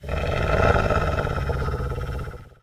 sounds_wolf_snarl.ogg